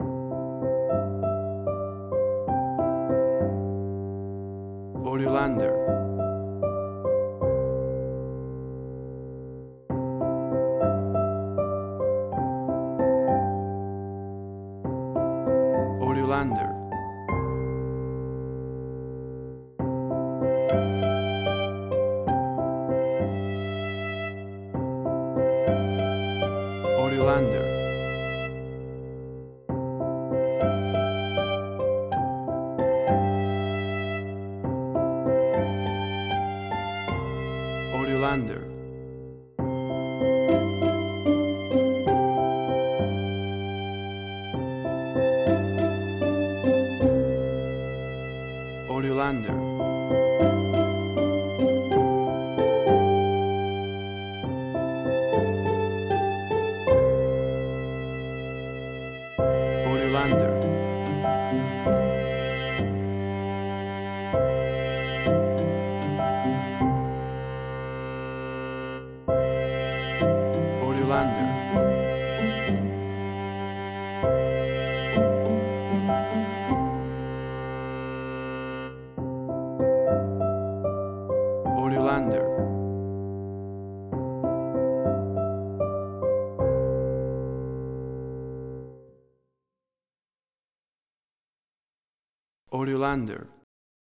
Indie Quirky
Tempo (BPM): 97